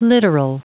Prononciation du mot littoral en anglais (fichier audio)
Prononciation du mot : littoral